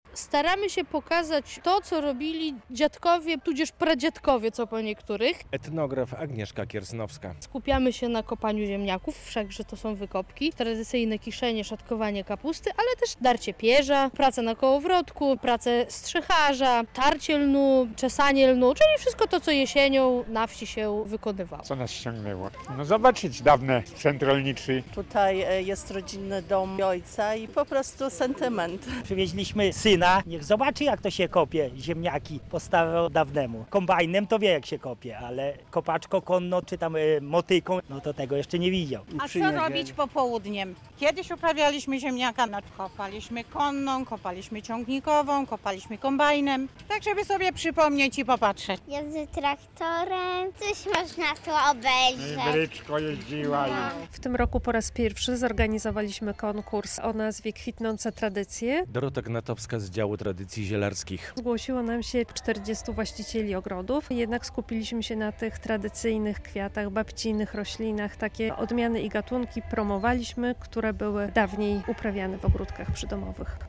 "Jesień w polu i zagrodzie" w Muzeum Rolnictwa w Ciechanowcu - relacja